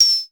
KLONE_PERC386.wav